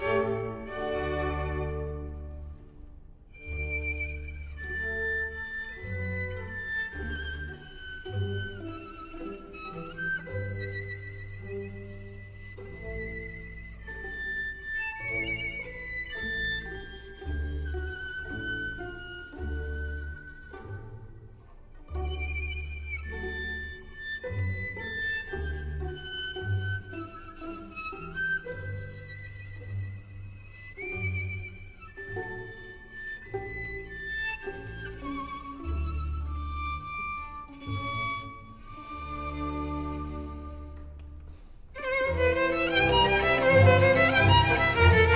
כלי נגינה - פורום למנגנים בכינור
חתכתי רק את הקטע של הפלז'ולט. קצת מזכיר קול של שריקה או חליל.